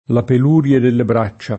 la pel2rLe delle br#©©a] (D’Annunzio) — tosc. peluia [pel2La], spec. nei sign. di «lanugine (di foglie o fiori o frutti)» e di «laniccio (sotto i mobili)»